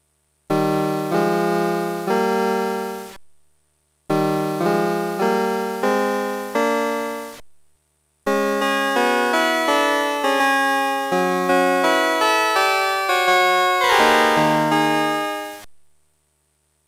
Test avec un ADSR plus long, un instrument